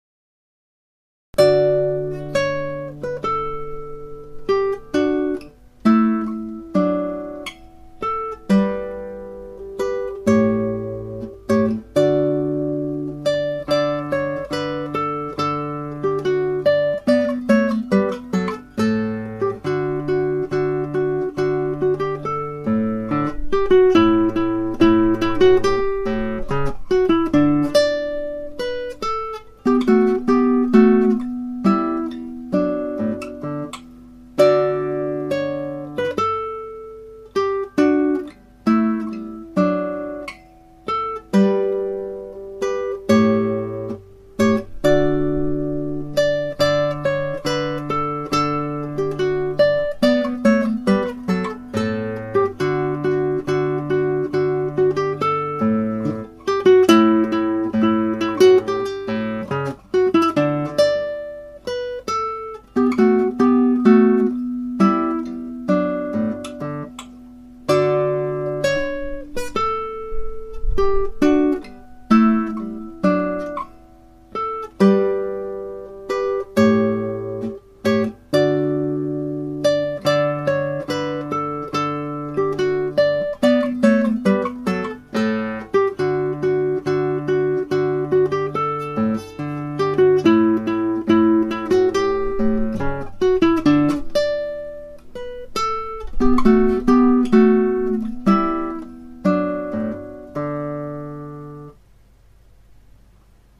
Guitar amatuer play